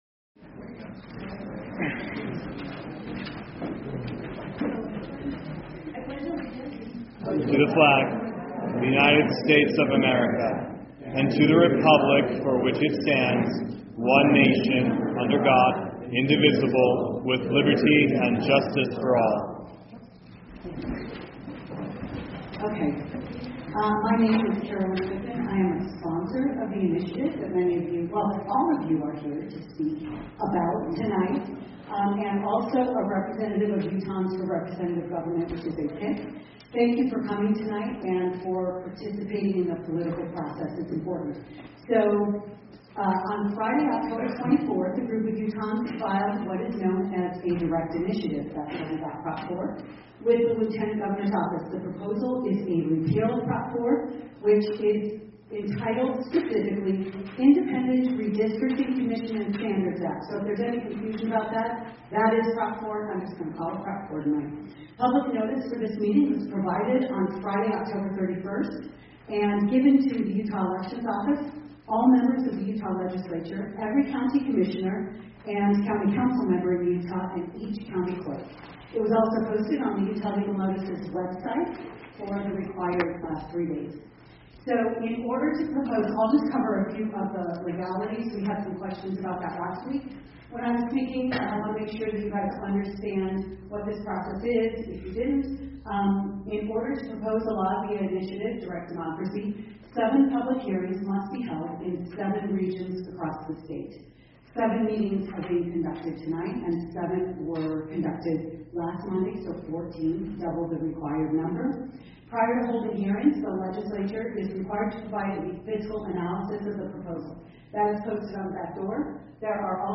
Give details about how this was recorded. Wasatch-Front-Public-Hearing-Nov.mp3